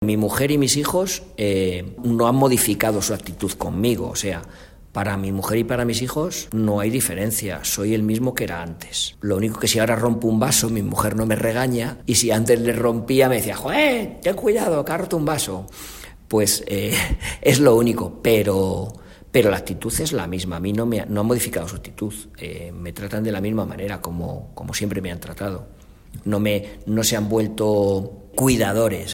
De hablar pausado y tranquilo